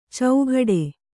♪ caughaḍe